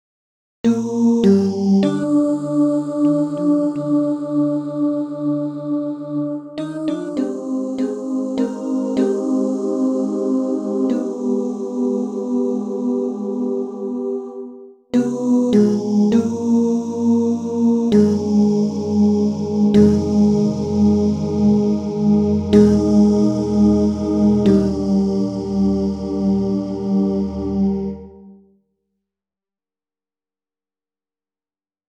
Key written in: E♭ Major